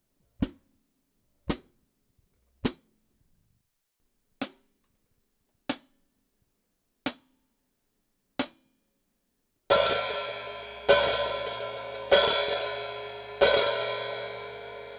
実際の録り音
ハイハット
57ドラムハット.wav